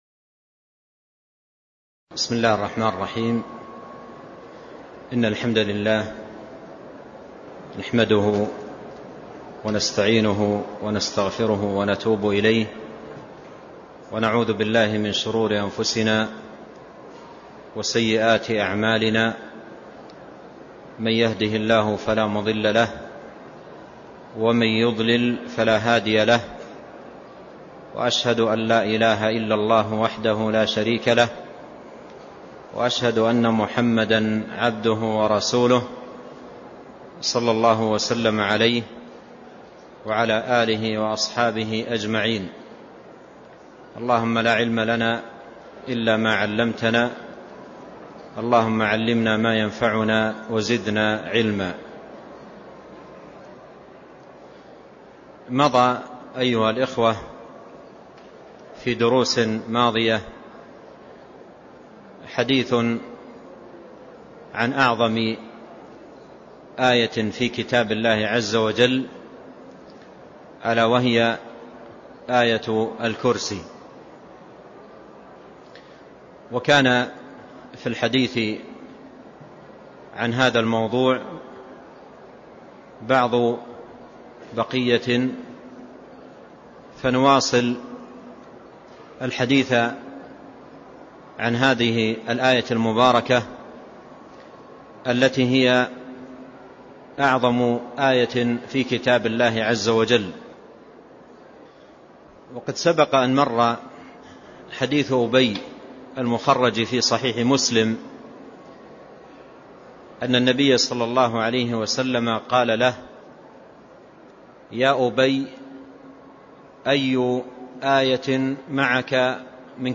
تاريخ النشر ٩ جمادى الآخرة ١٤٢٧ هـ المكان: المسجد النبوي الشيخ